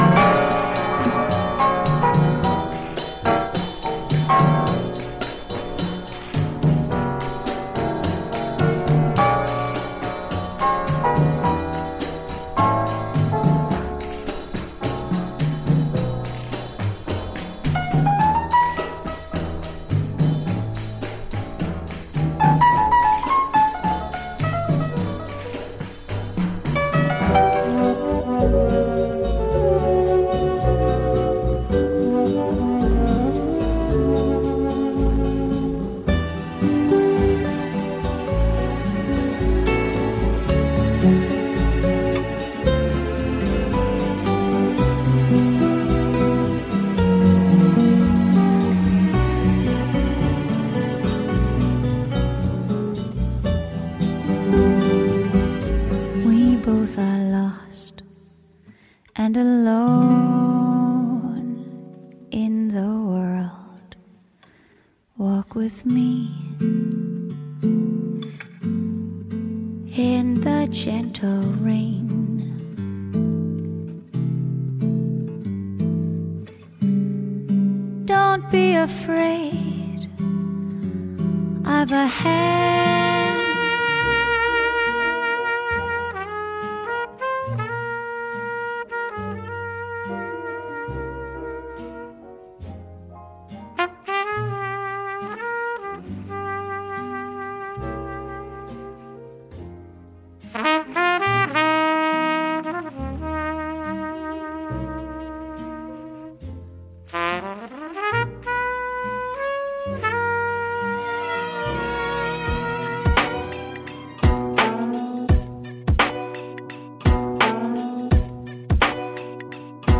Hotel Lobby Mellow Vibe
Blend of Bossa Nova, Lounge and Jazz Music